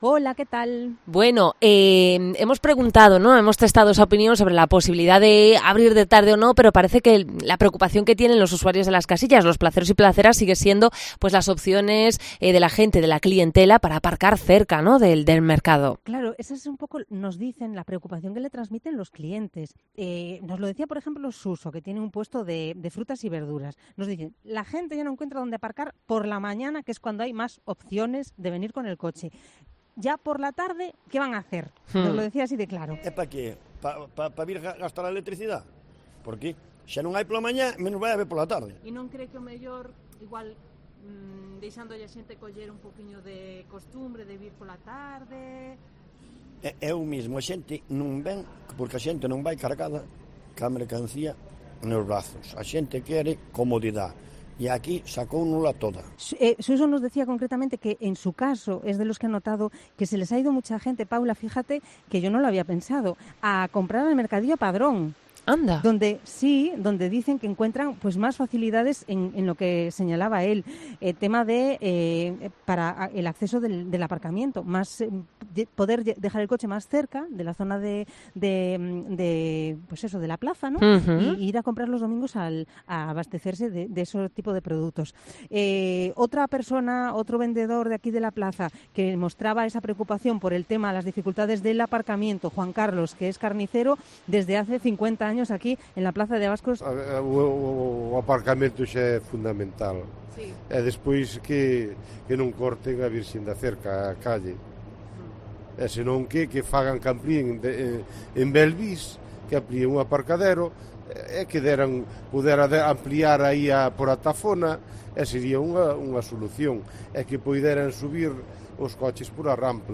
Herrera en Cope Santiago se acercó hoy a la Plaza de Abastos de Santiago